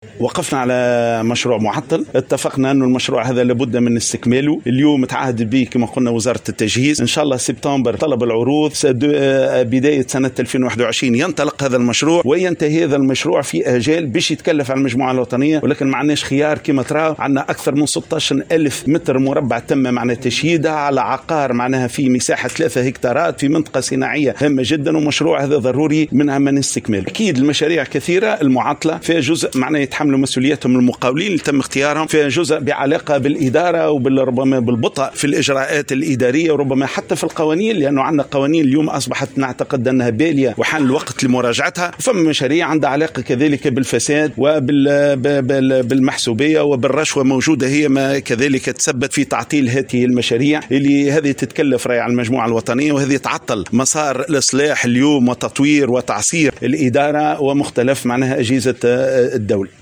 وأشار في تصريح اليوم لمراسل "الجوهرة أف أم" على هامش زيارة ميدانية إلى موقع مشروع المجمع التقني بالعقبةإلى عدم إيفاء بعض المقاولين بالصفقات وإلى الإجراءات الإدارية والقوانين البالية، إضافة إلى المحسوبية والفساد.